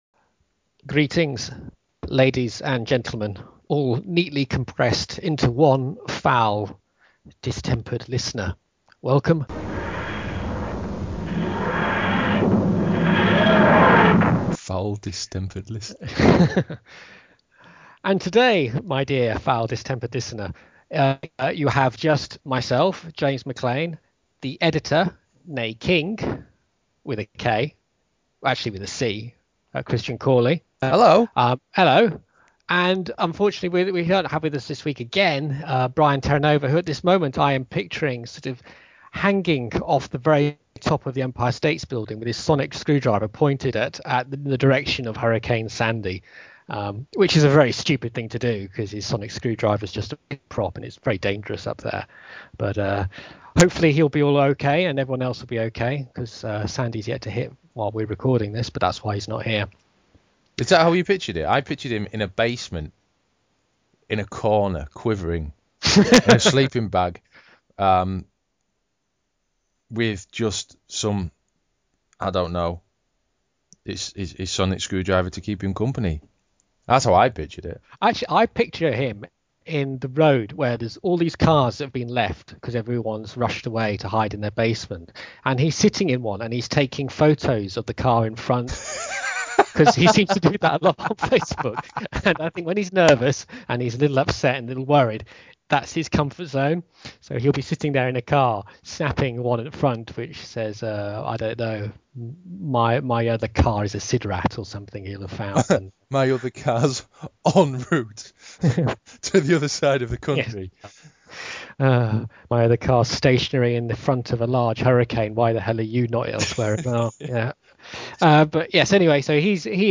chat about recent news developments